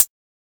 edm-hihat-50.wav